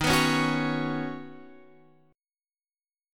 Ebm7#5 chord